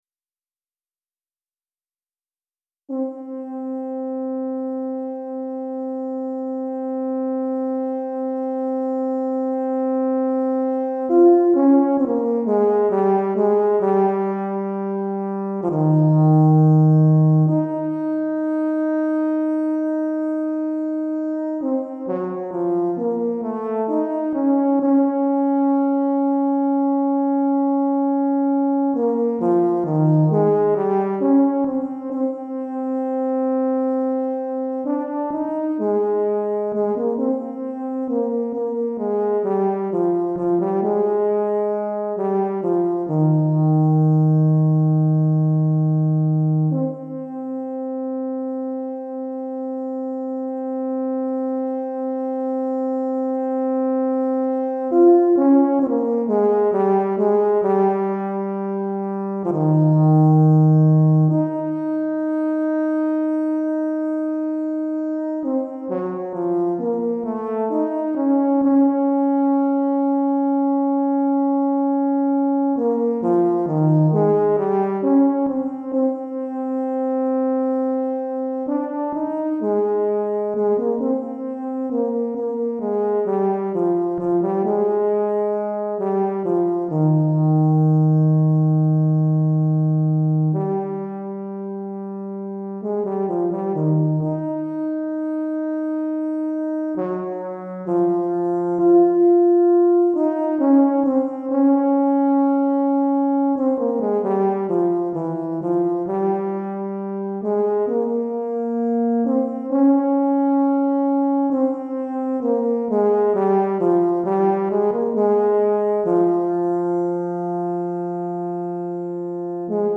Tuba Solo